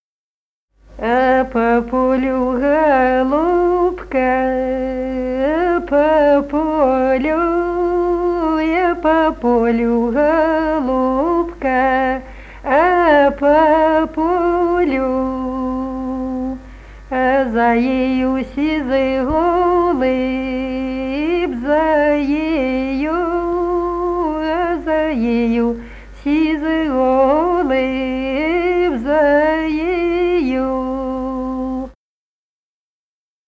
Народные песни Смоленской области